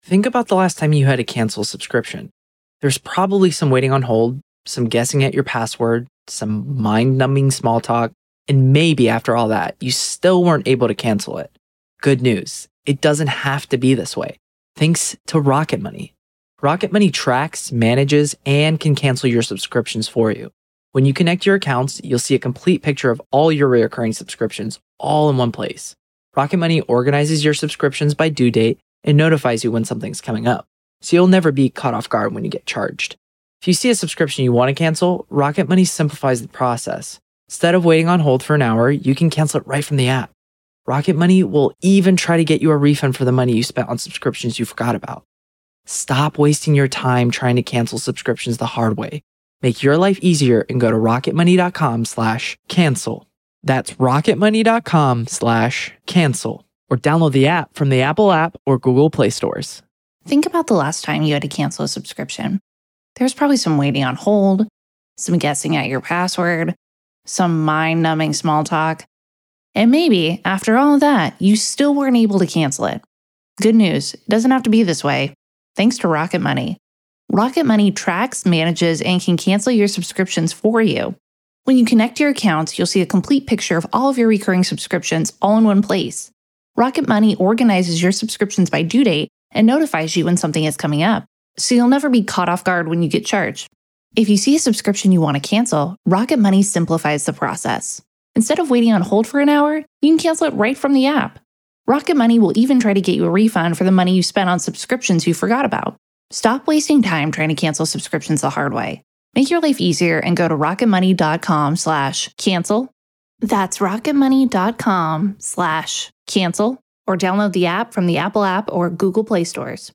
True Crime Today | Daily True Crime News & Interviews / Bryan Kohberger: Coincidence or Calculated?